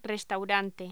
Locución: Restaurante
Sonidos: Voz humana